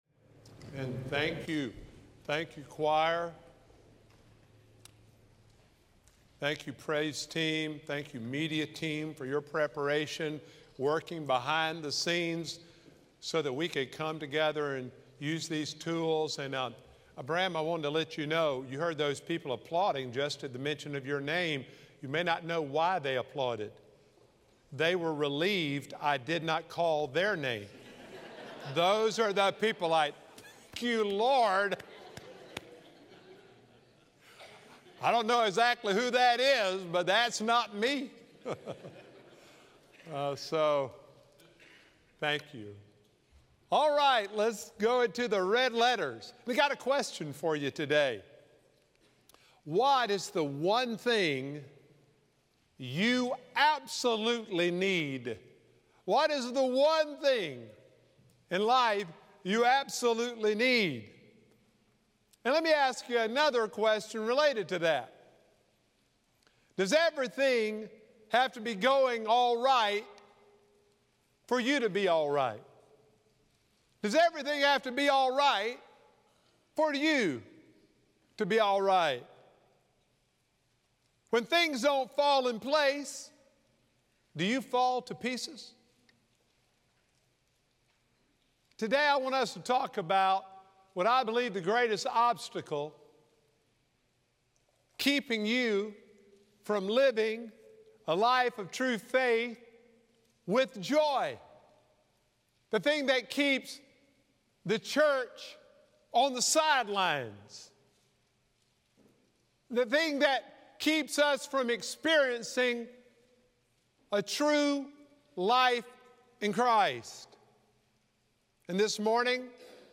Sermons
August-4-2024-Sermon-Audio.mp3